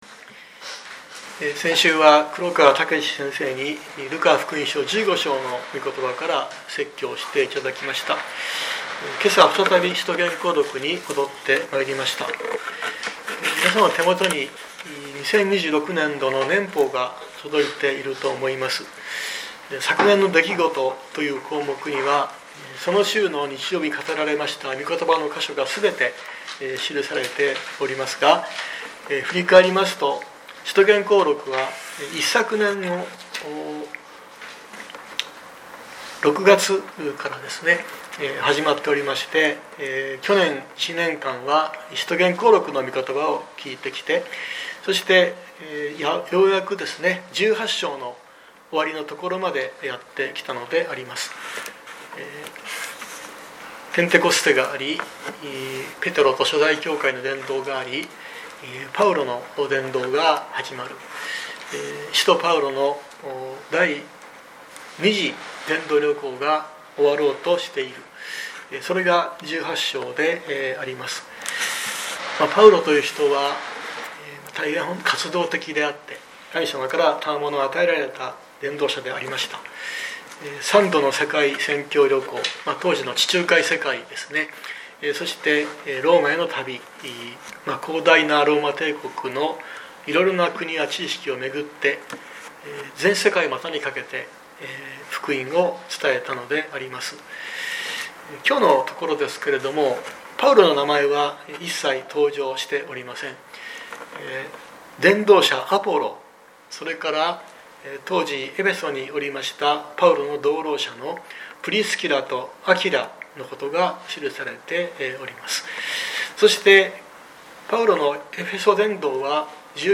2026年01月18日朝の礼拝「雄弁家アポロ」熊本教会
熊本教会。説教アーカイブ。